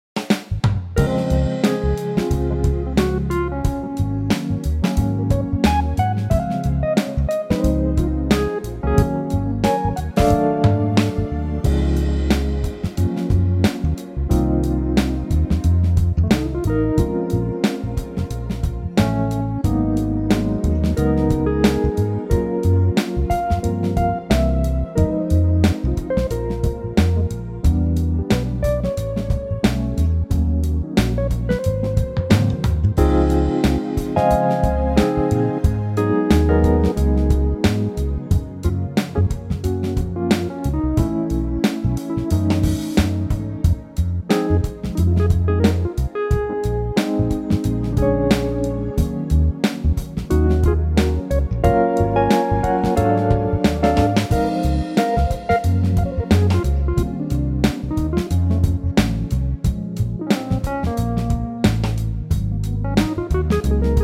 Unique Backing Tracks
key - Db - vocal range - Db to Eb